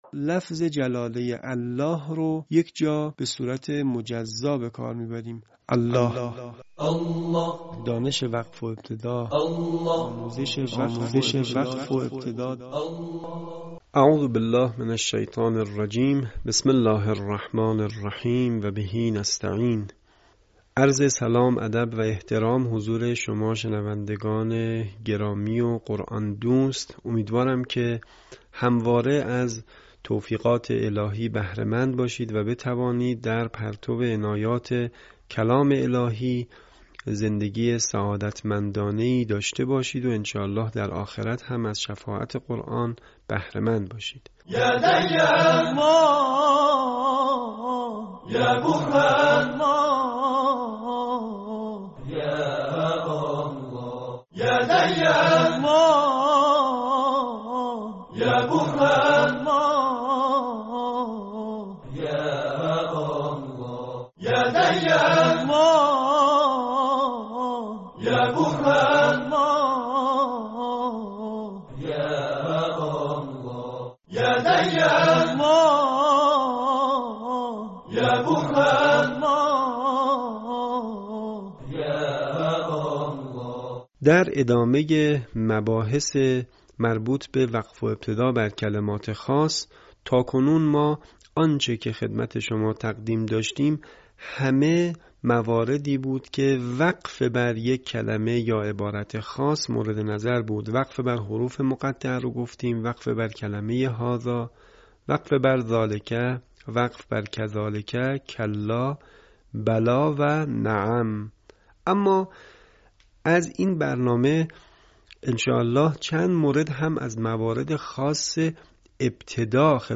یکی از مهم‌ترین سیاست‌های رسانه ایکنا نشر مبانی آموزشی و ارتقای سطح دانش قرائت قرآن مخاطبان گرامی است. به همین منظور مجموعه آموزشی شنیداری(صوتی) قرآنی را گردآوری و برای علاقه‌مندان بازنشر می‌کند.